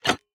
Minecraft Version Minecraft Version latest Latest Release | Latest Snapshot latest / assets / minecraft / sounds / block / copper_trapdoor / toggle4.ogg Compare With Compare With Latest Release | Latest Snapshot
toggle4.ogg